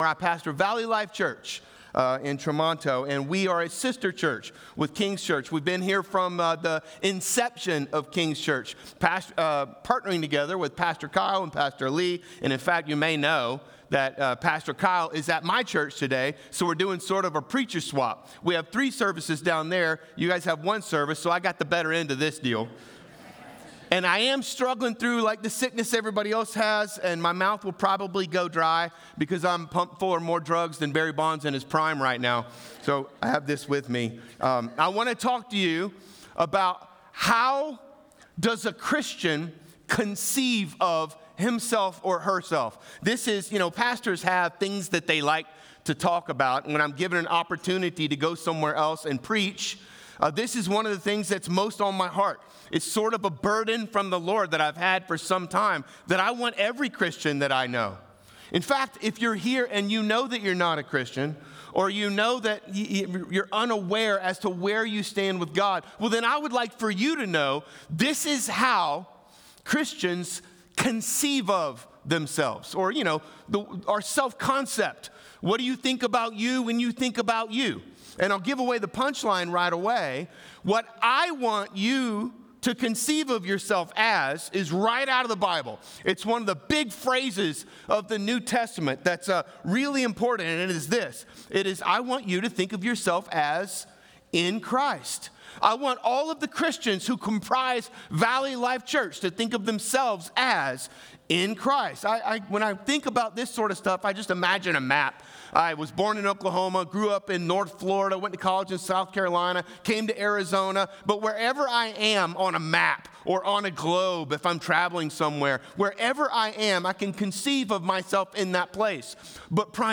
Feb8Sermon.mp3